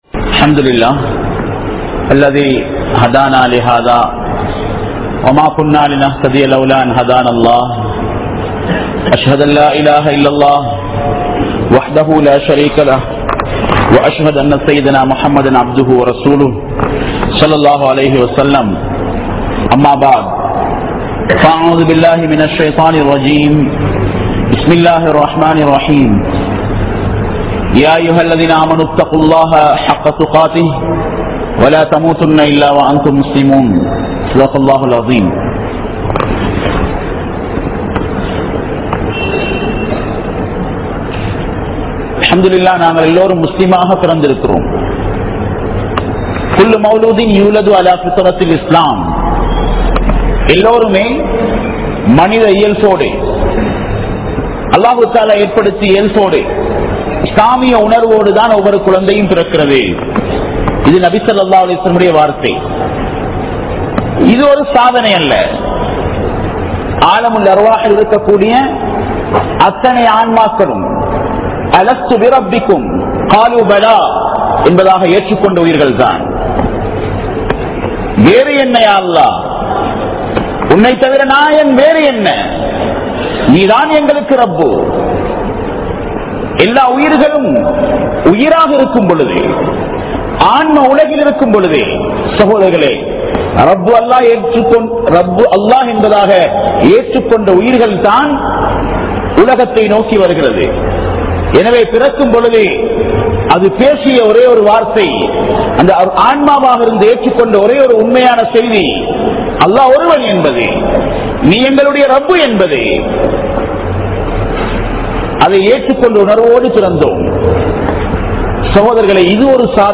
Manithanin Iruthi Nimidam(Sakrath) | Audio Bayans | All Ceylon Muslim Youth Community | Addalaichenai